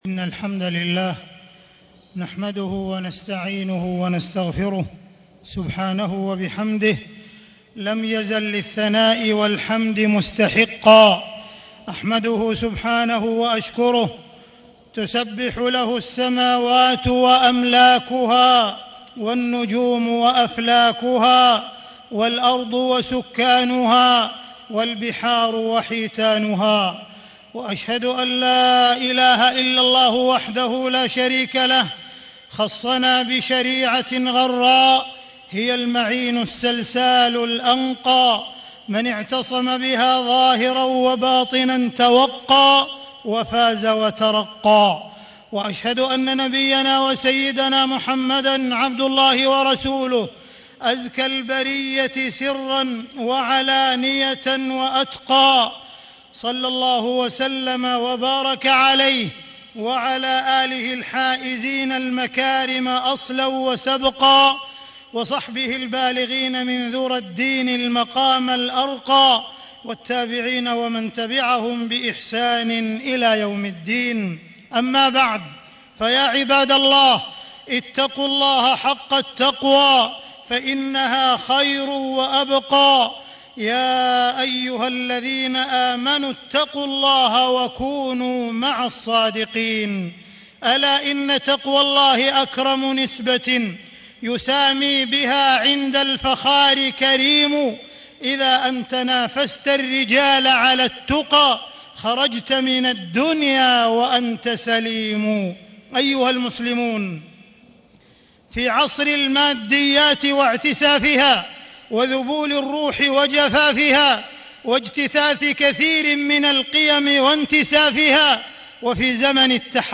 تاريخ النشر ١١ ربيع الأول ١٤٣٦ هـ المكان: المسجد الحرام الشيخ: معالي الشيخ أ.د. عبدالرحمن بن عبدالعزيز السديس معالي الشيخ أ.د. عبدالرحمن بن عبدالعزيز السديس التدين الزائف صوره وسماته